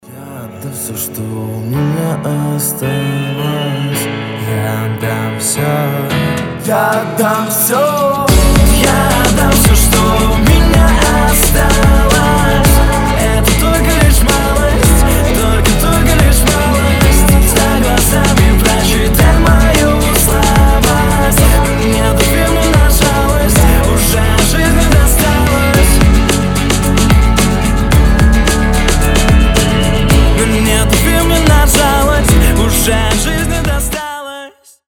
• Качество: 320, Stereo
лирика
красивый мужской голос
дуэт